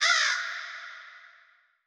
RAVEN 1.wav